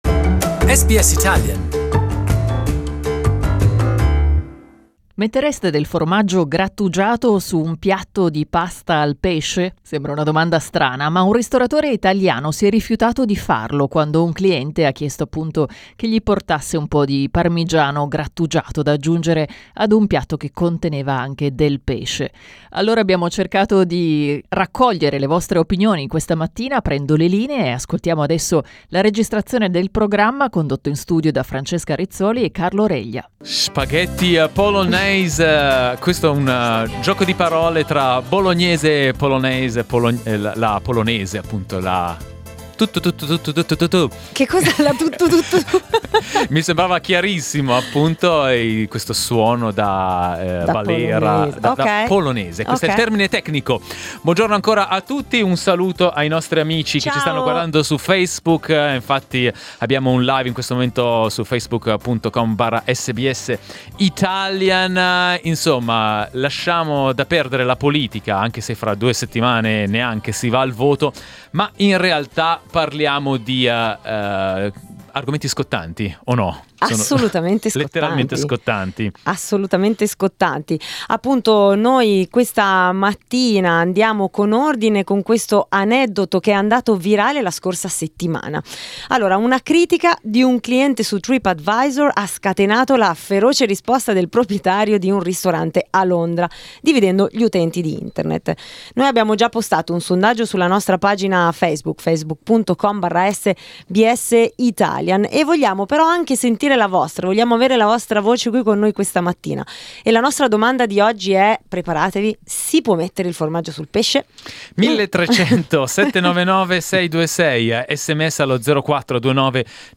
An Italian restaurateur refused to give grated Parmesan cheese to a customer who wanted to add it to a fish-based pasta dish. The discussion became very public when the customer wrote a review on TripAdvisor to express his outrage, to which the restaurateur responded with a fiery message. We asked our listeners what they thought of the debate, with the help of our studio guest